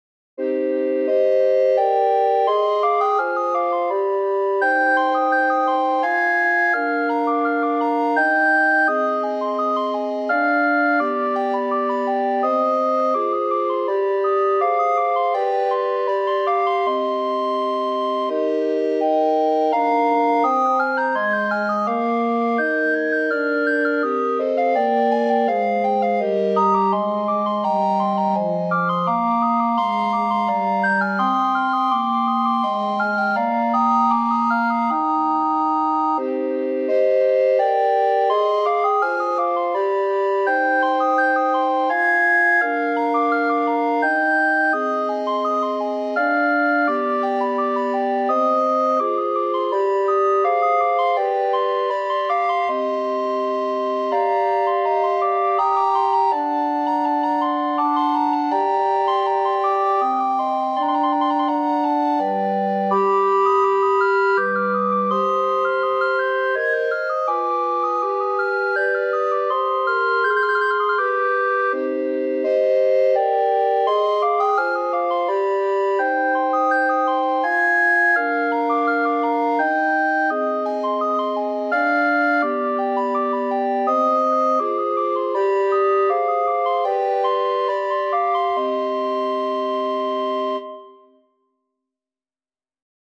arranged here for 4 recorders
and has been arranged here for four recorders.